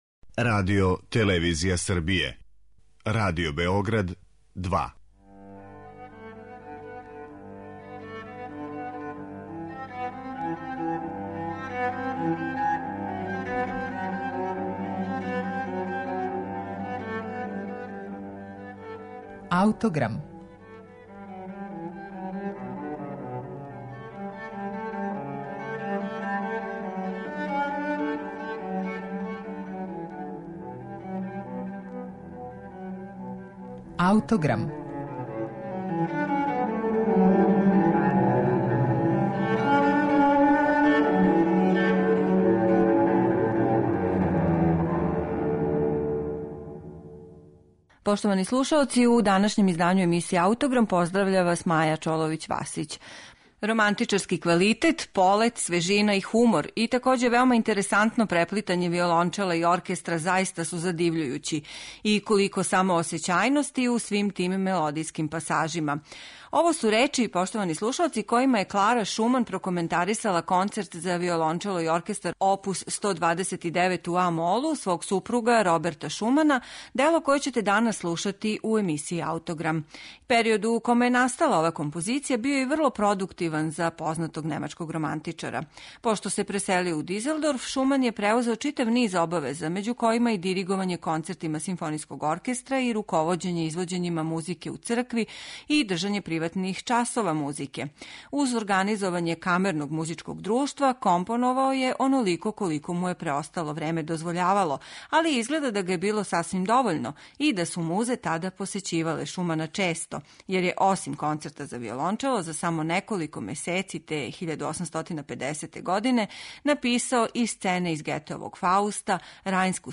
Концерт за виолончело у а-молу, оп. 129, Роберта Шумана
Три става која се свирају без паузе